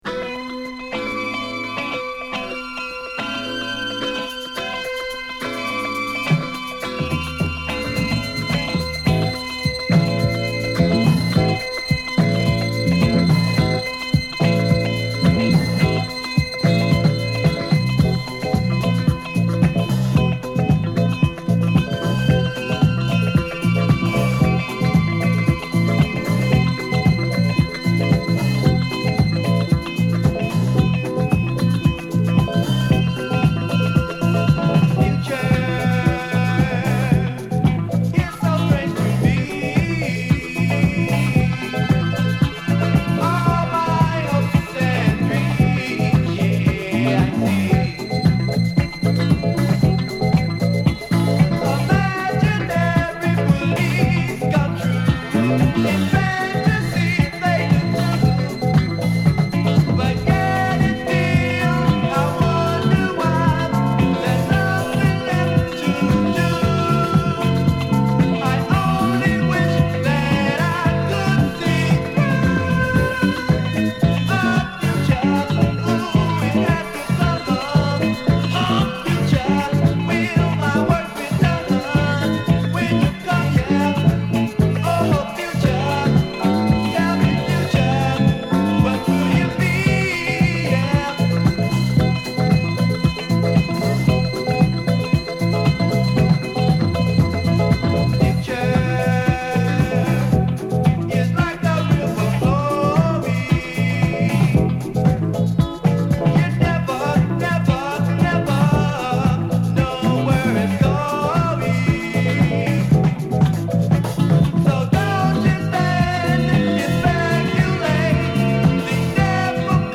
究極のメロウ・ダンサー／クロスオーヴァー・ソウル
インスト・コズミック・ファンク